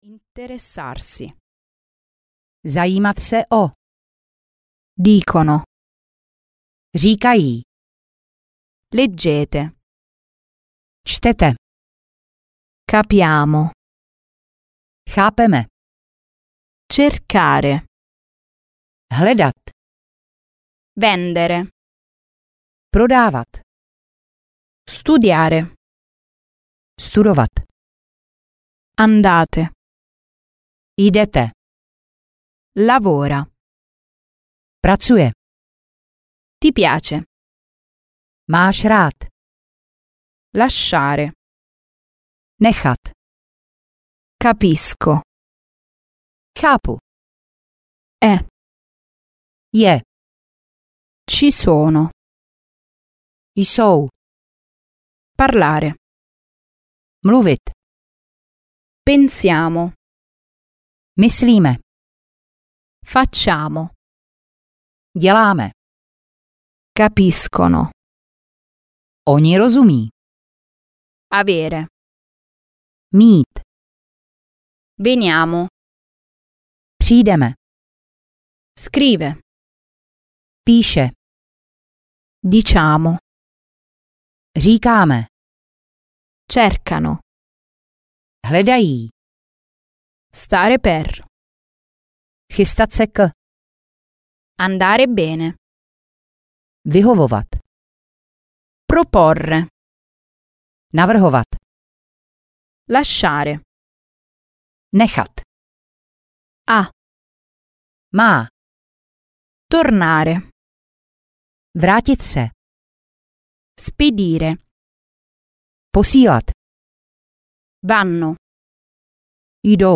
Italština pro začátečníky A1, A2 audiokniha
Ukázka z knihy